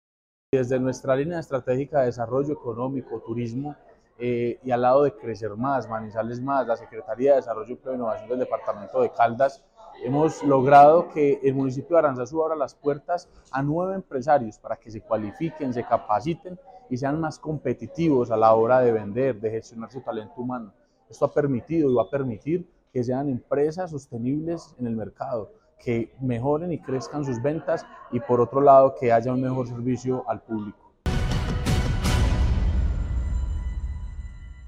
Sebastián Merchán Zuluaga – Alcalde del municipio de Aranzazu.